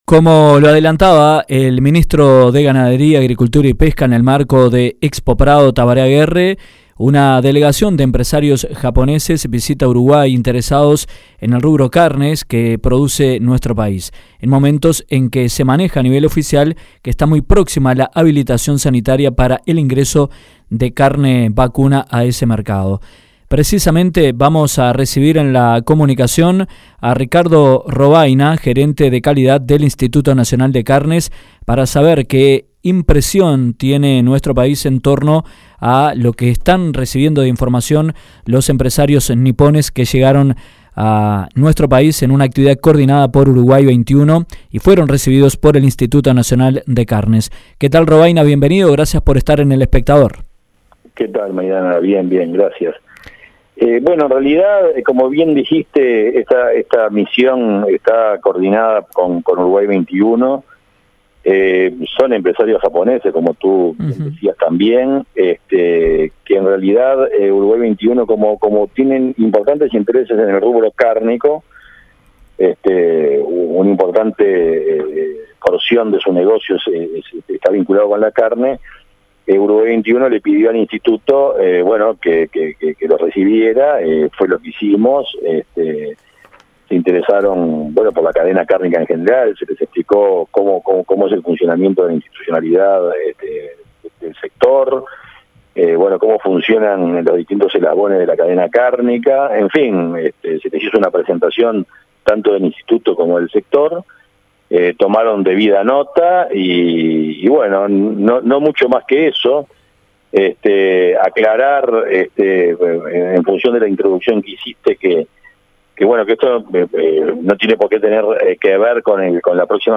En entrevista con Dinámica Rural